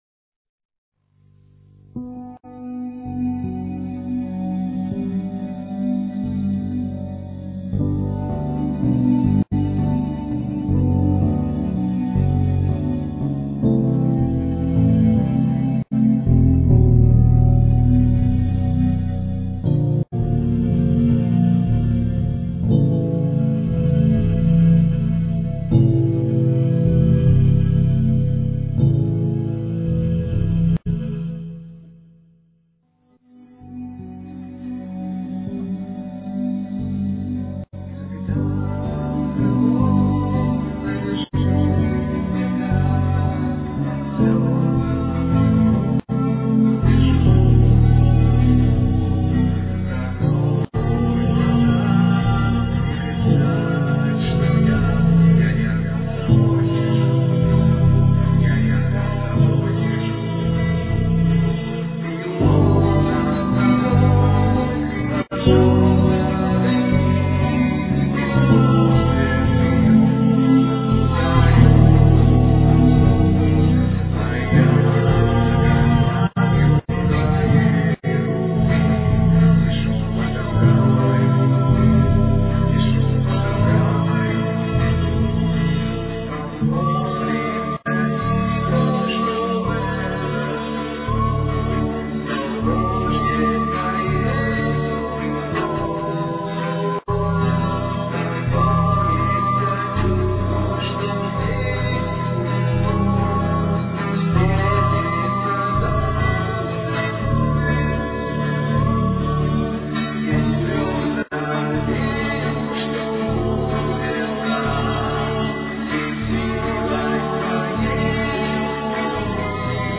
on piano